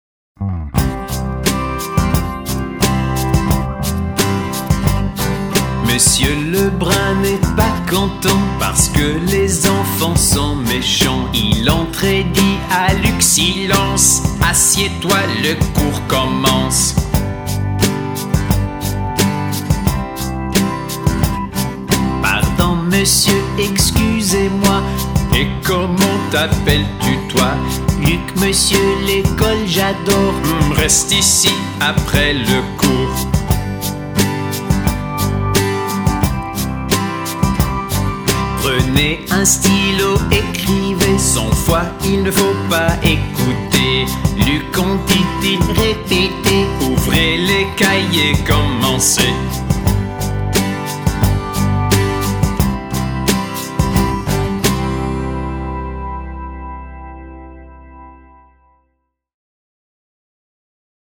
Monsieur Lebrun n'est pas content song